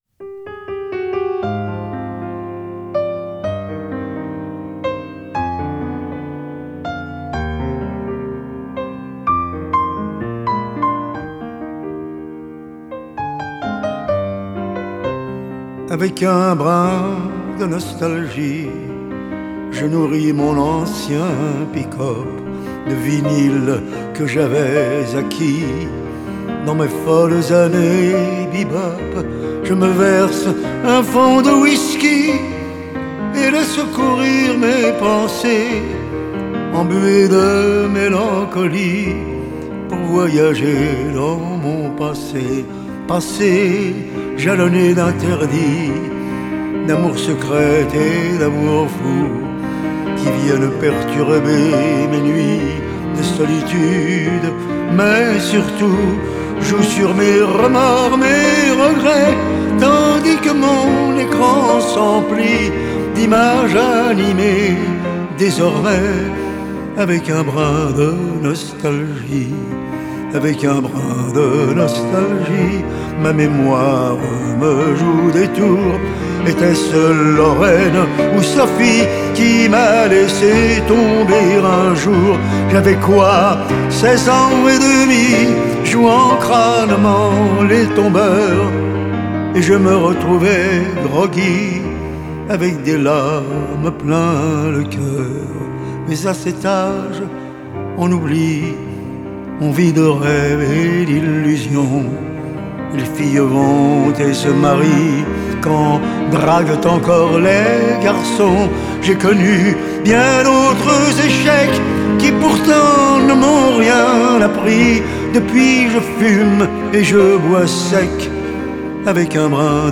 Genre: Chanson